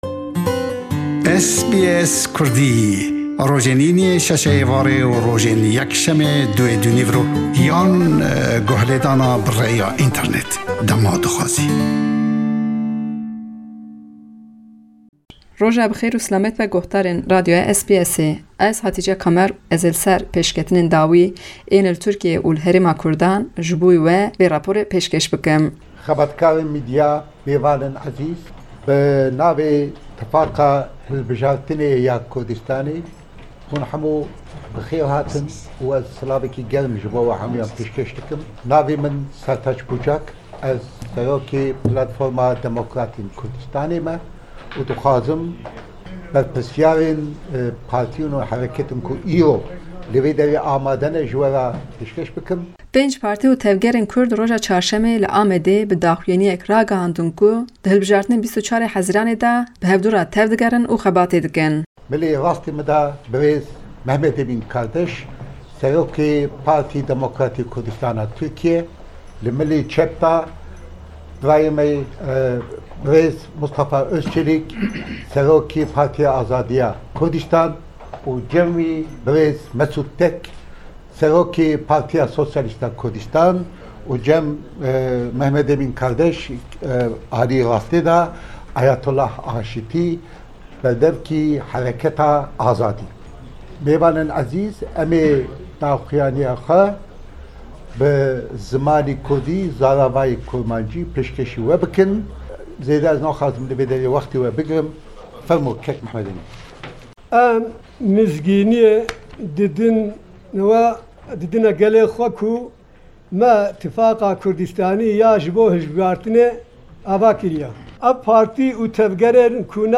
Rapora